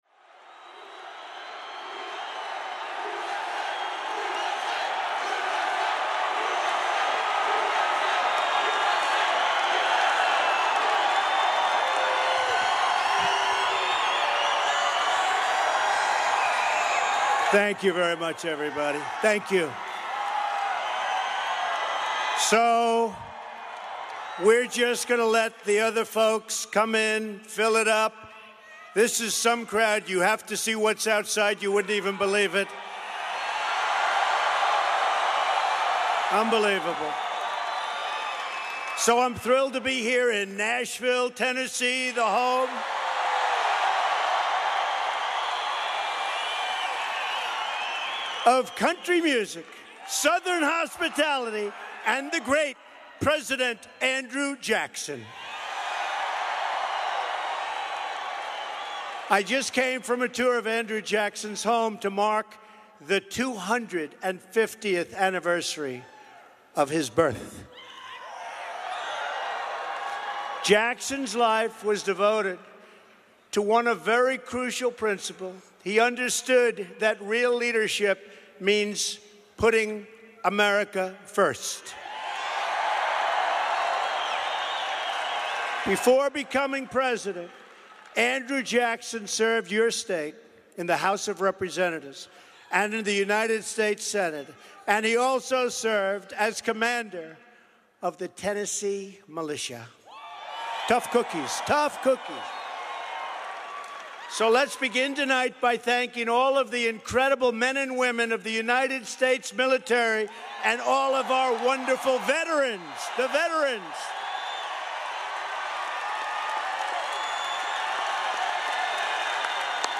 Held in Nashville, Tennessee.